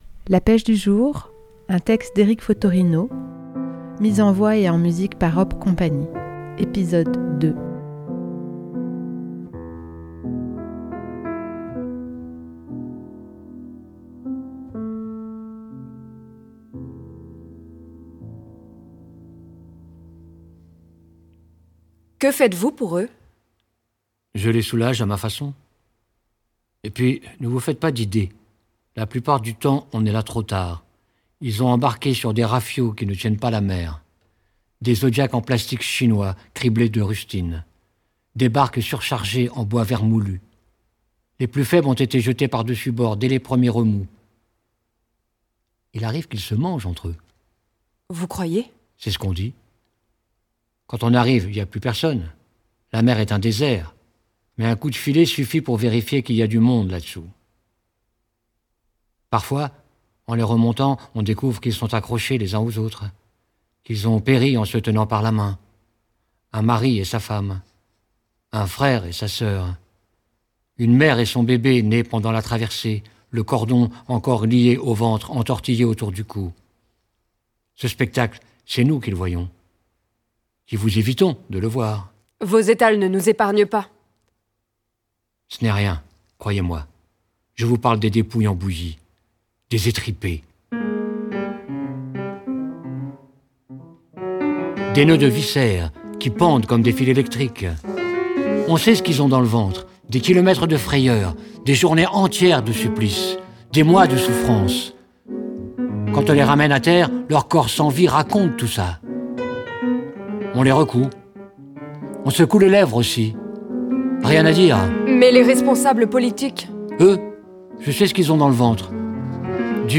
Hop Cie met en scène et en musique le texte coup de poing d'Eric Fottorino "La pêche du jour".